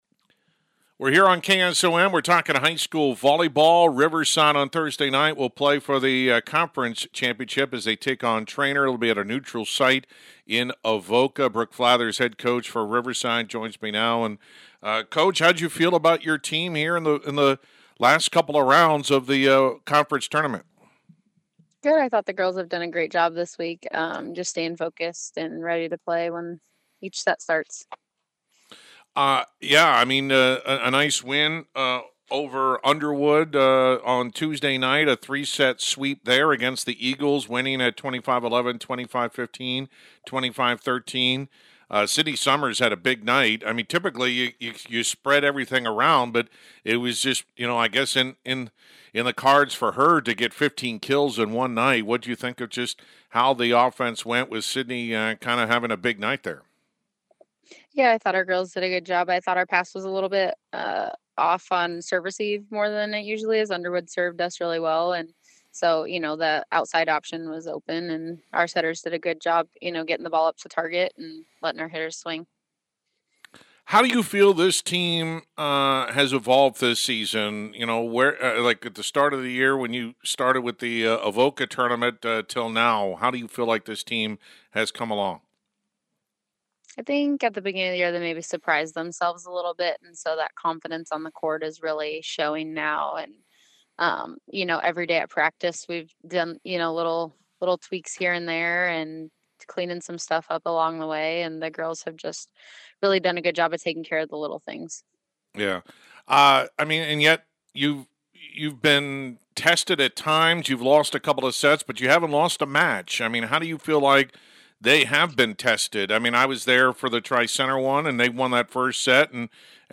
Complete Interview
riverside-volleyball-10-15.mp3